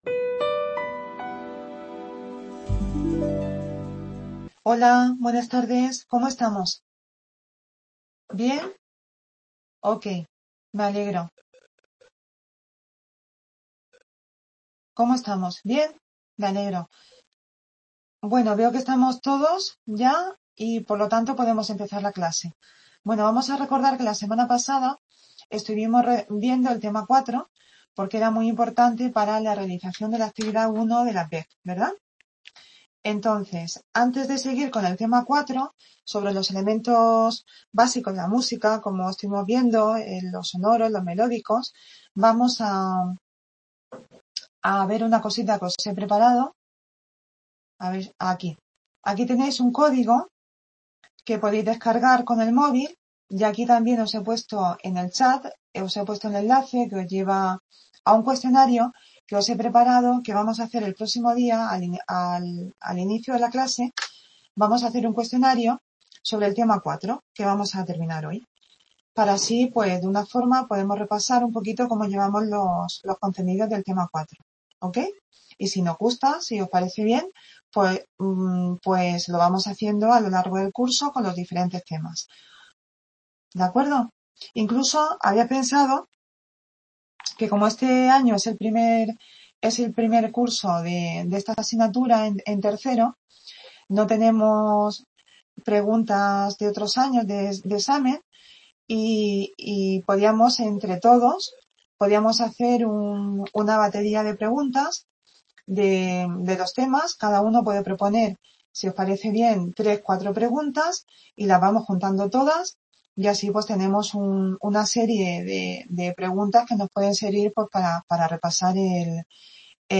Tutoría
Video Clase